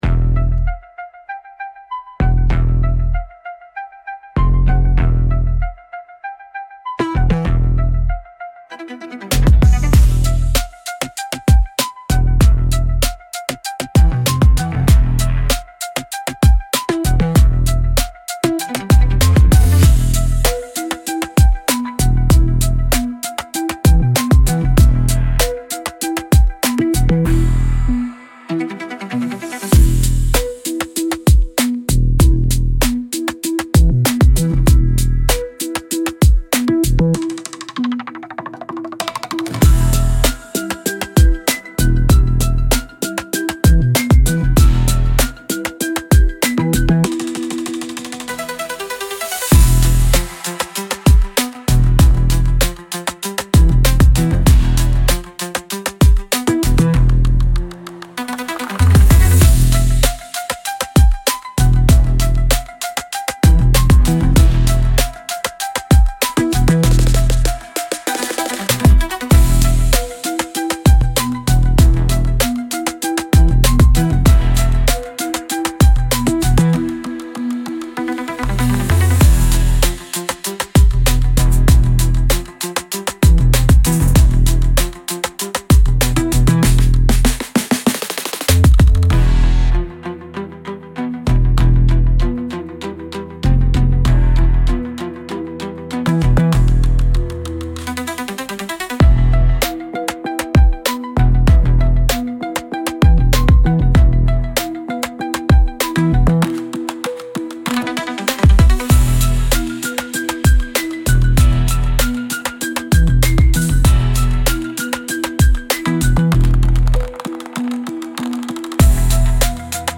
Instrumental - Static Heart - Grimnir Radio